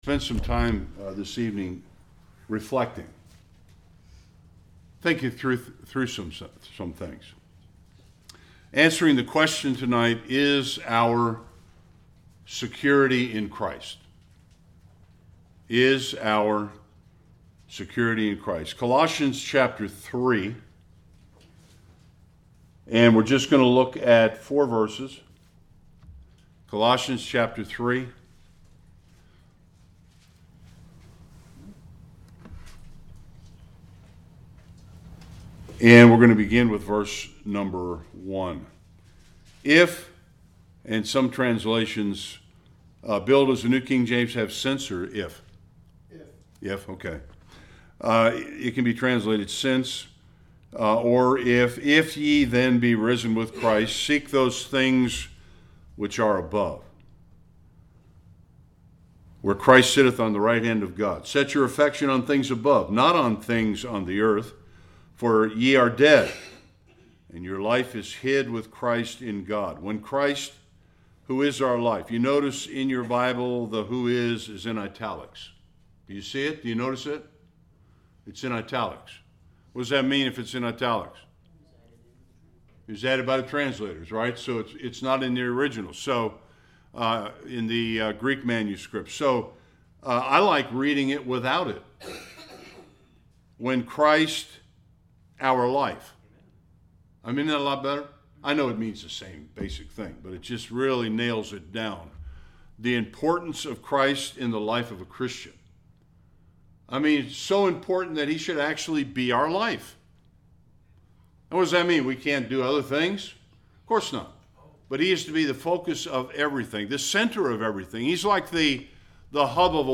1-4 Service Type: Bible Study We all put our confidence and security in something or someone.